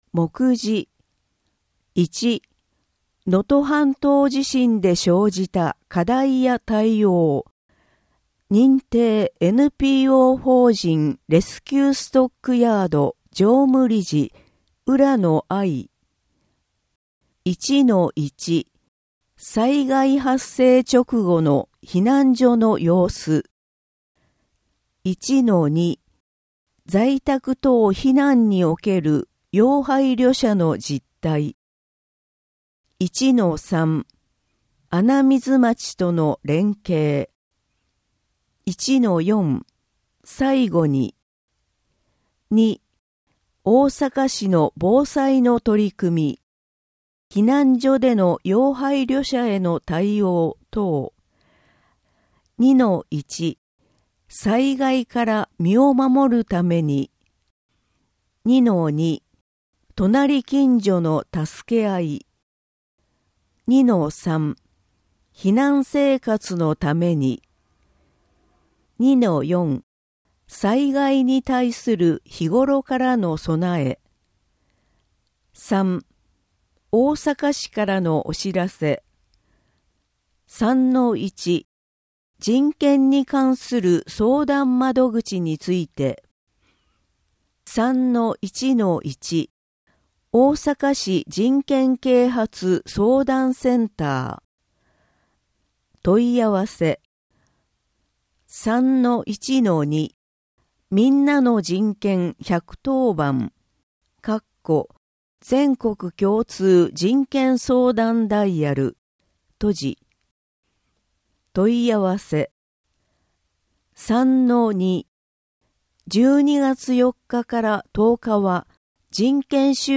本号につきましては、淀川区で活動されている「音訳ボランティアグループこもれび」の有志の皆様に作成いただきました。